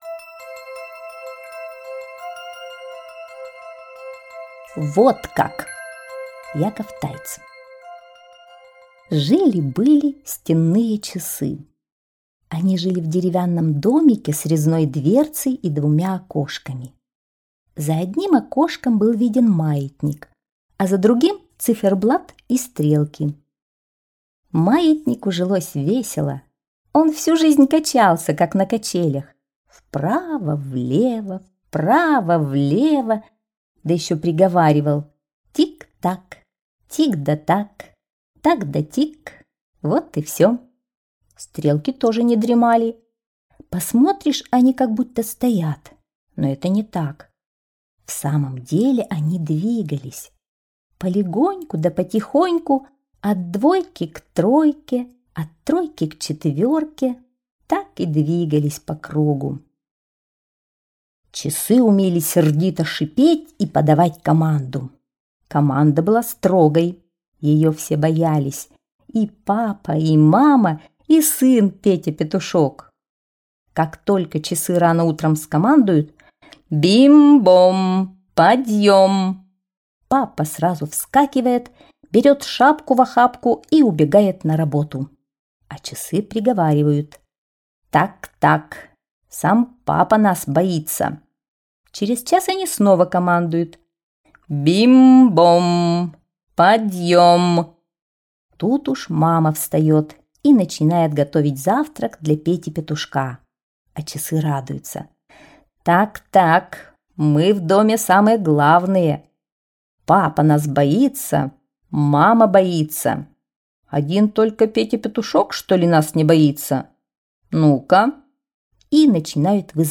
Вот как! - аудиосказка Тайца Я.М. Сказка про настенные часы.